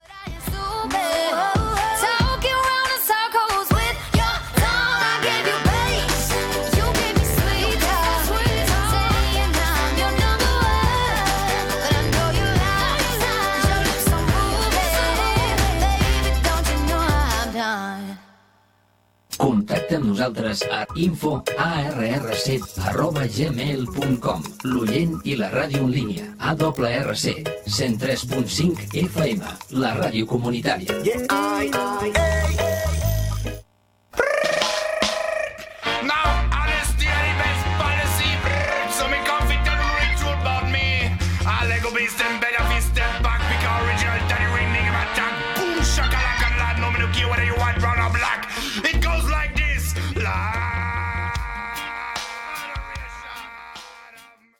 f7de886a939cbece727e47fffbad700cdd1bc414.mp3 Títol Ràdio ARRC Emissora Ràdio ARRC Titularitat Tercer sector Tercer sector Musical Descripció Música, adreça electrònica i identificació de l'emissora. Data emissió 2023-03-11 Banda Internet Localitat Torelló Comarca Osona Durada enregistrament 00:54 Idioma Català Notes Escoltada al Canal de Twitch de Ràdio ARRC.